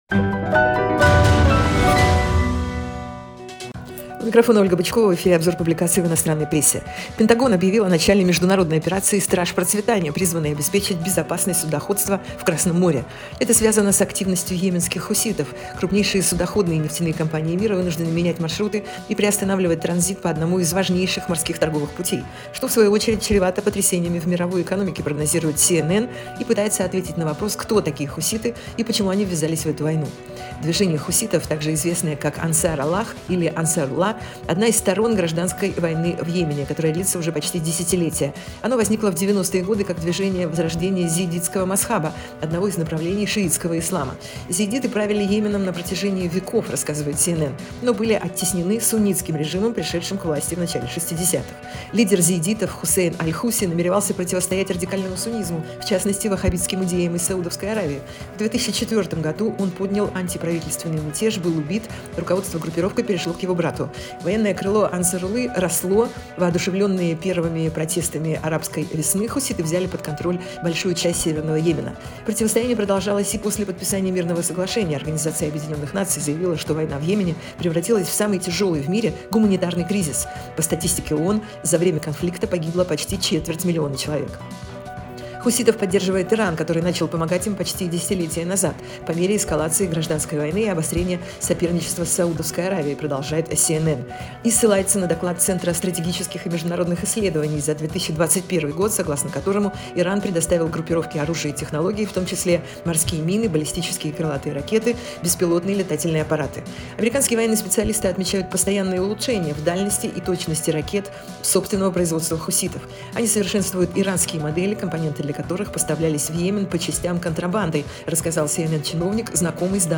Обзор инопрессы 19.12.2023